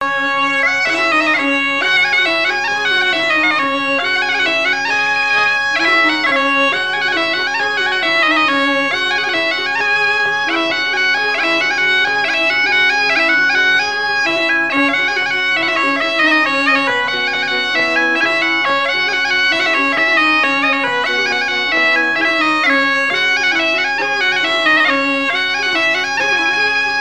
Mémoires et Patrimoines vivants - RaddO est une base de données d'archives iconographiques et sonores.
Air n° 3 par Sonneurs de veuze
Airs joués à la veuze et au violon et deux grands'danses à Payré, en Bois-de-Céné
Pièce musicale inédite